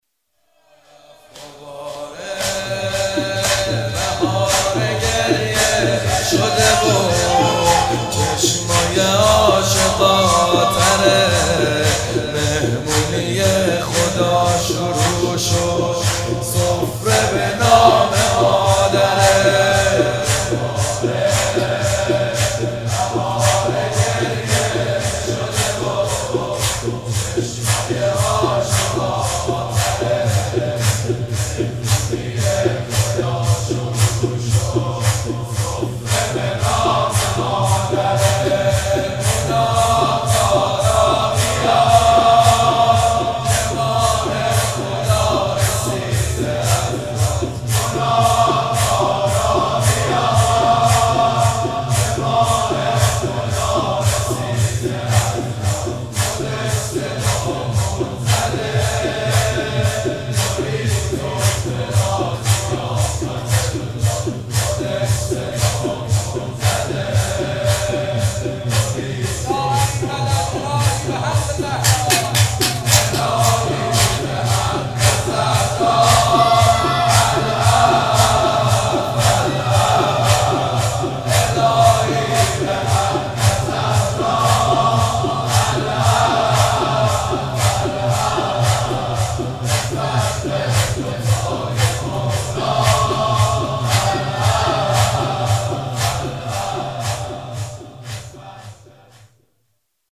هئیت رزمندگان غرب تهران/رمضان96